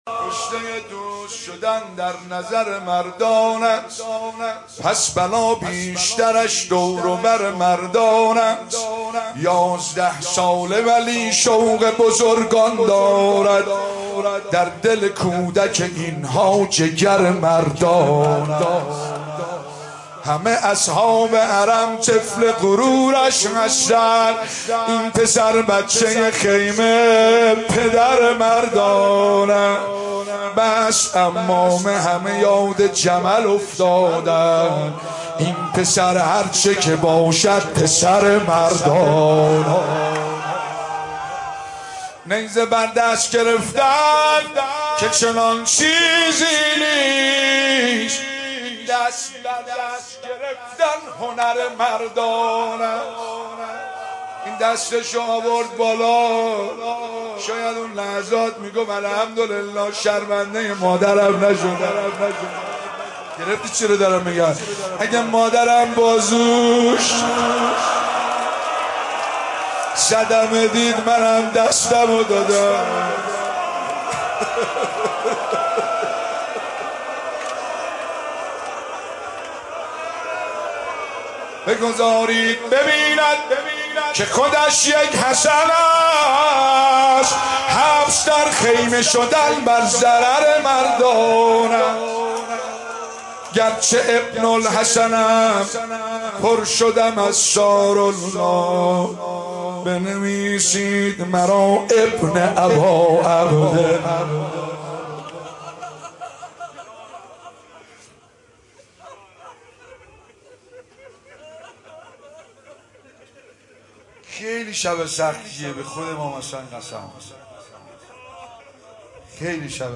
نوحه جديد
مداحی صوتی